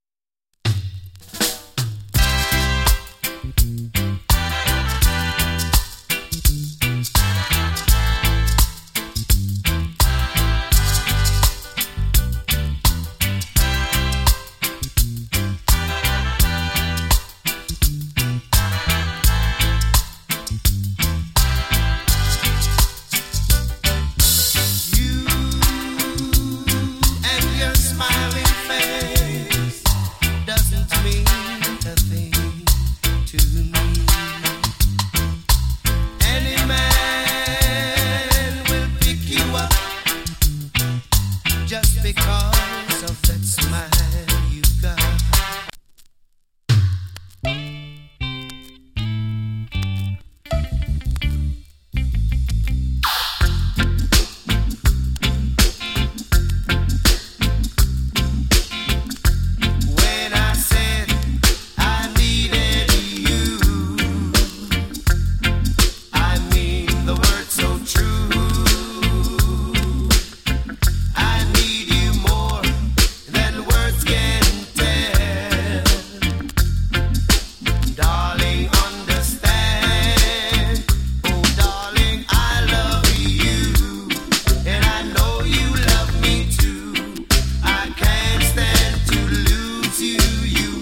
FINE DISCO MIX !